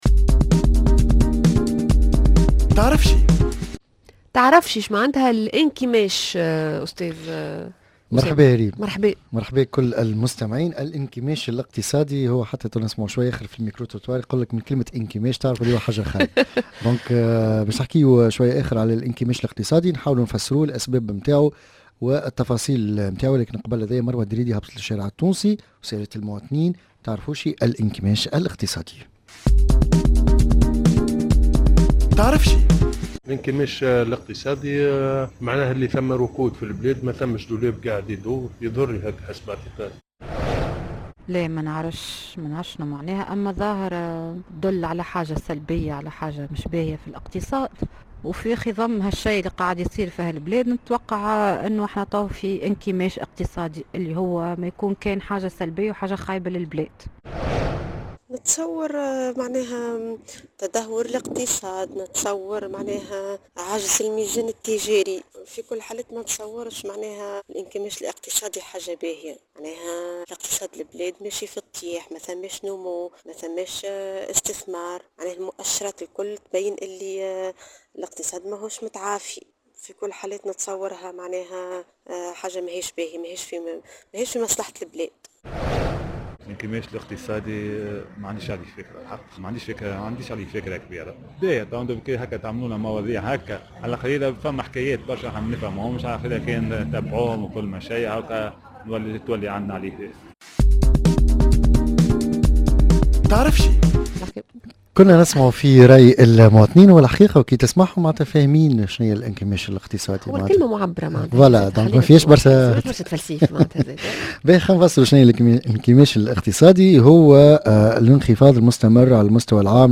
Micro trottoir